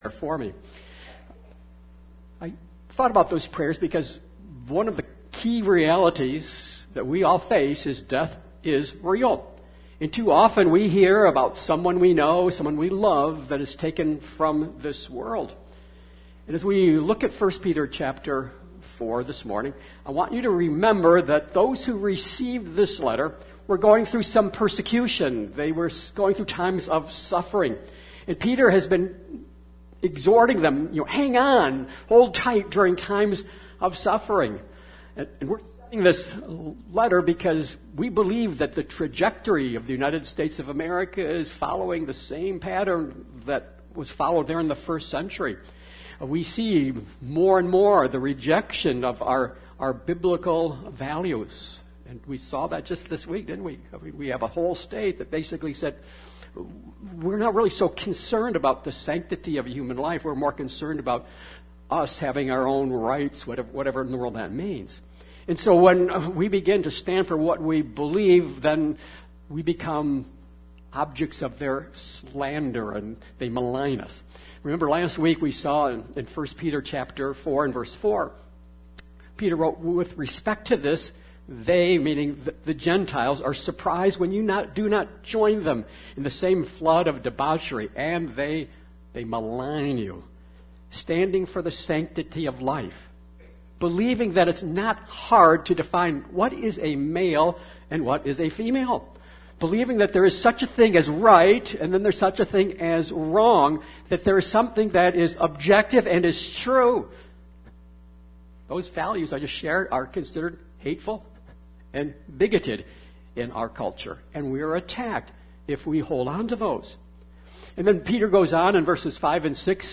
Nov 13, 2022 As We Live Before the End MP3 SUBSCRIBE on iTunes(Podcast) Notes Sermons in this Series 1 Peter 4:7-11 Thank You, Peter!